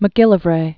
(mə-gĭlə-vrā), Alexander 1750?-1793.